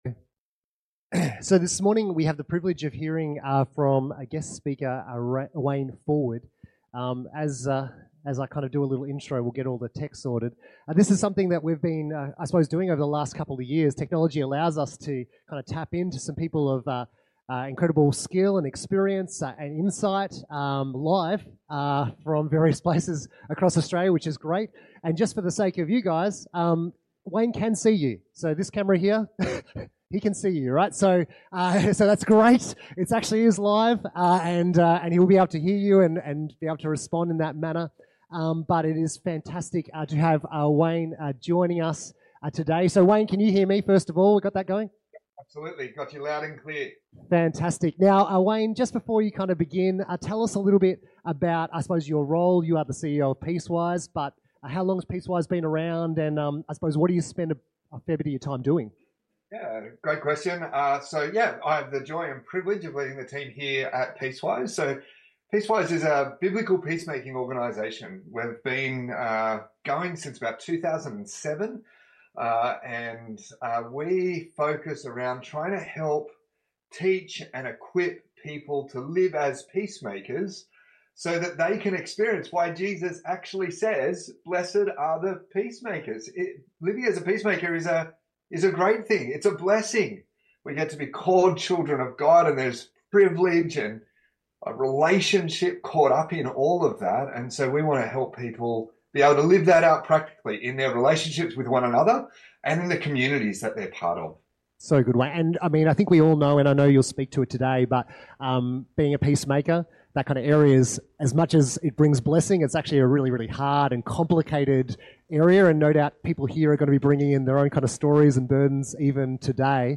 Listen to all the latest sermons from the team at Alice Springs Baptist Church, located in the heart of Australia.